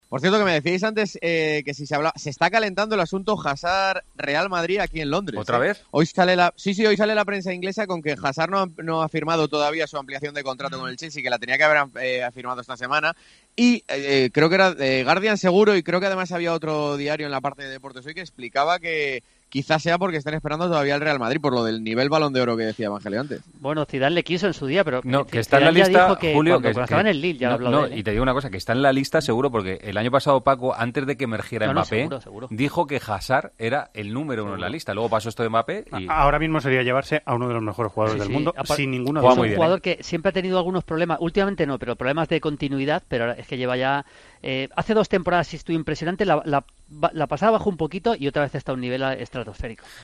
ha informado en Deportes COPE desde Londres en la previa del Chelsea-Atlético